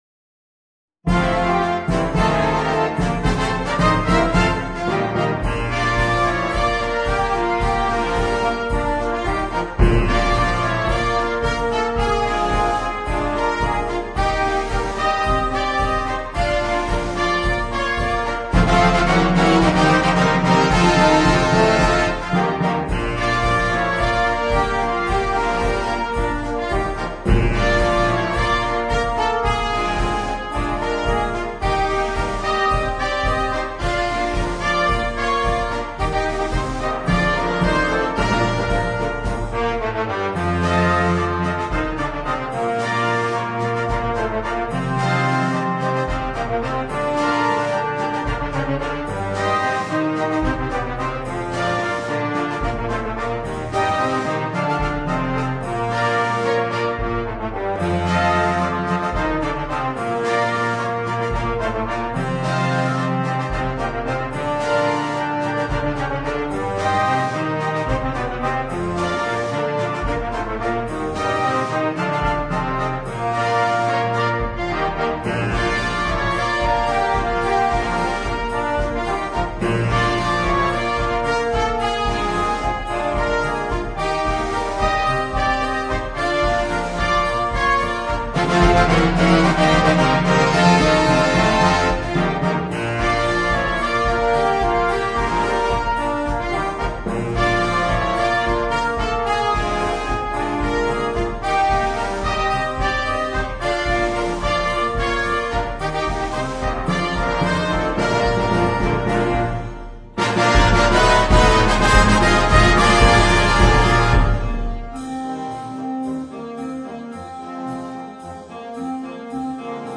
Dixie da concerto
MUSICA PER BANDA